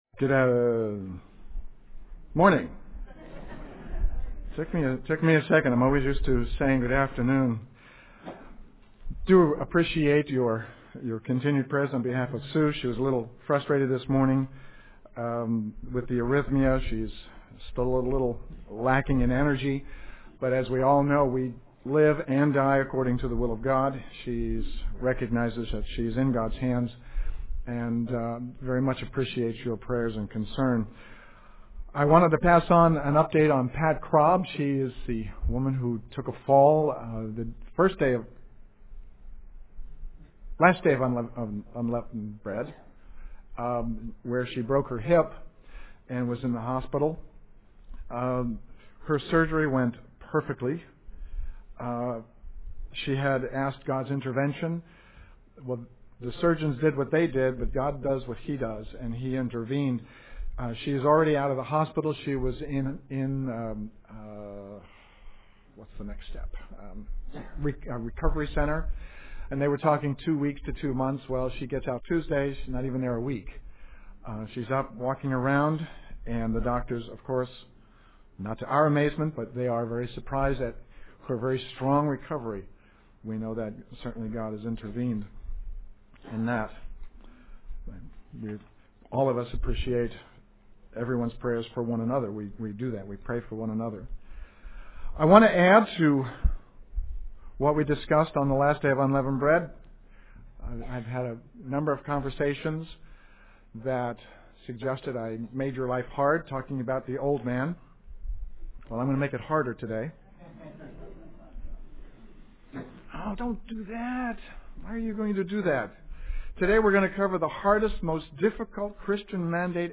Given in San Francisco Bay Area, CA